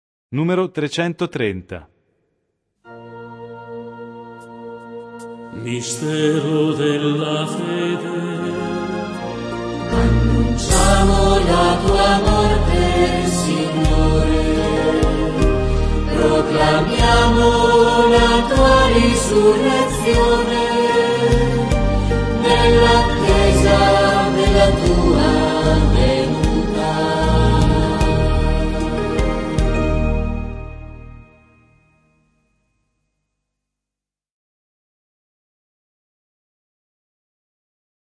e due nuove melodie del